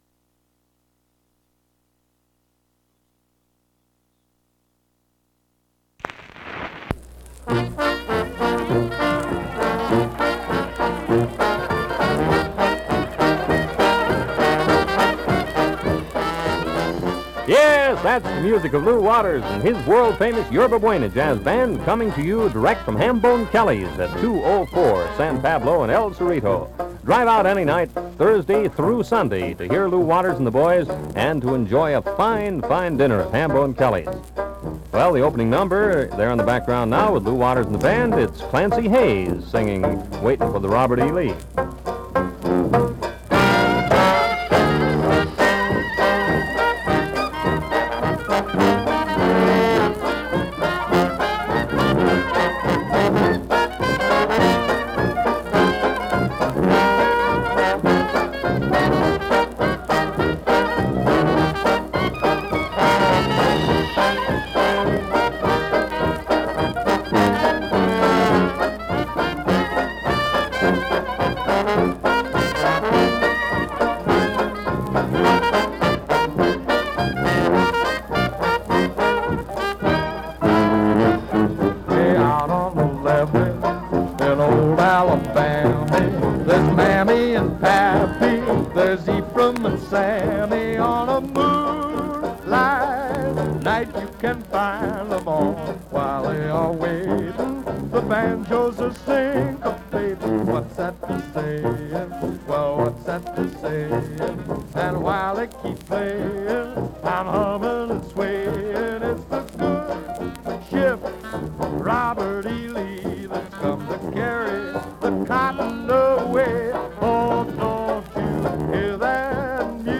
relay live performances